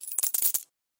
На этой странице собраны звуки, связанные с покупками и оплатой: работа кассового аппарата, сигналы терминалов, уведомления об успешной транзакции.
Звук приобретения монет в игре